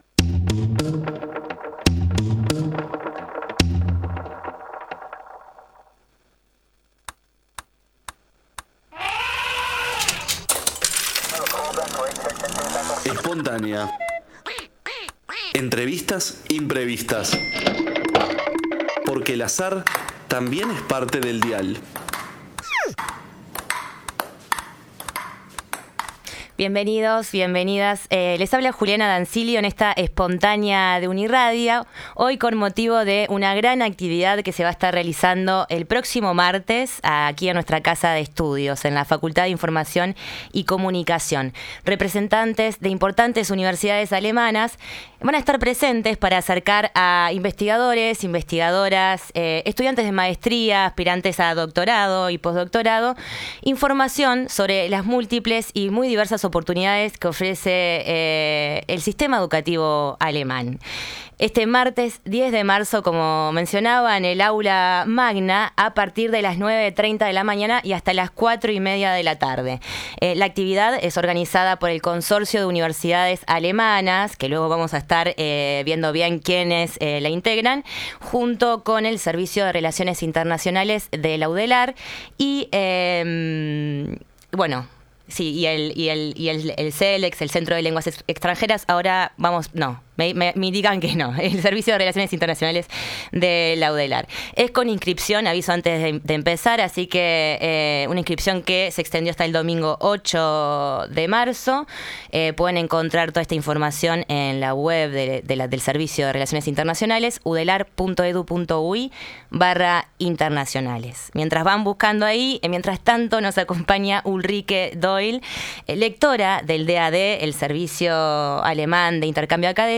Entrevista disponible en YouTube.